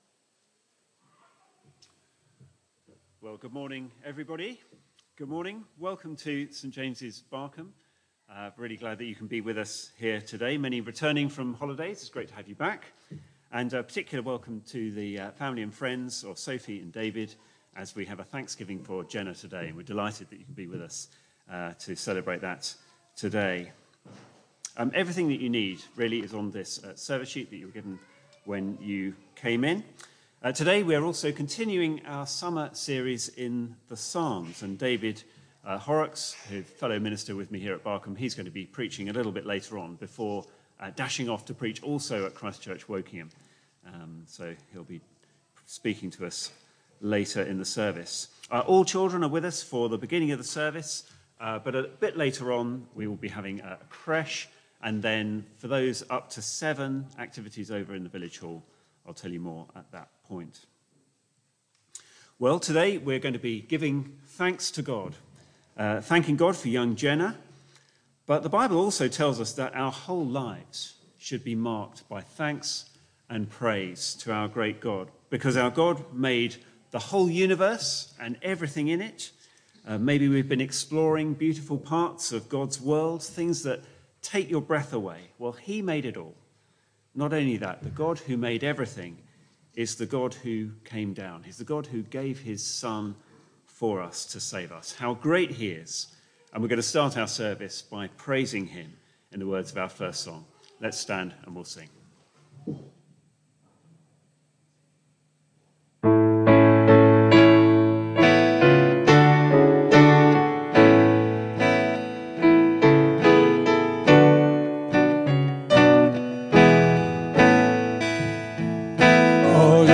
Media for Barkham Morning Service on Sun 27th Aug 2023 10:00
Full service recording